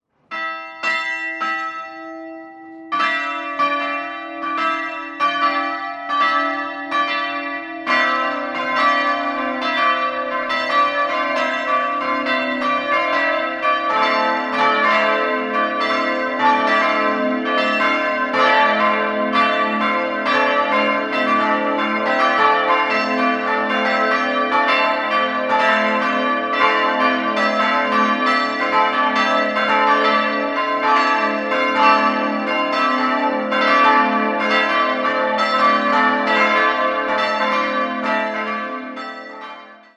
4-stimmiges ausgefülltes A-Moll-Geläute: a'-c''-d''-e''